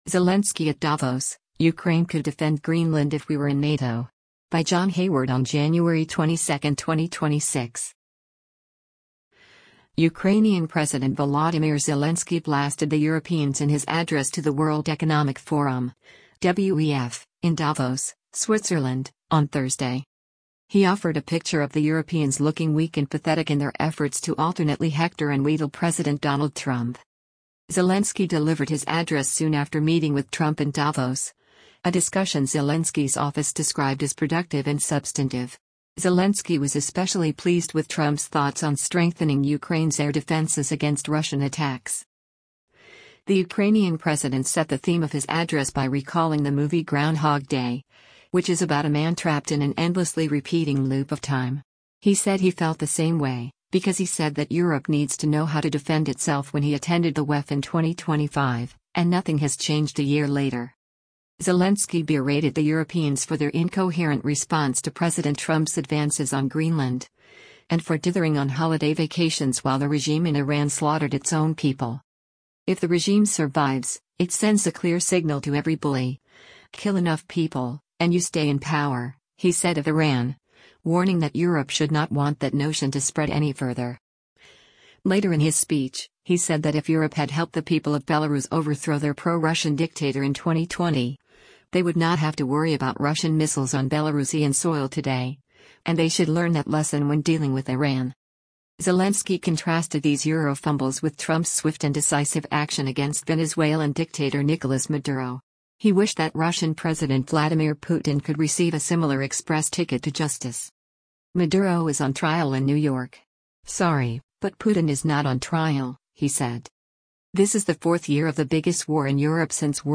Ukrainian President Volodymyr Zelensky blasted the Europeans in his address to the World Economic Forum (WEF) in Davos, Switzerland, on Thursday.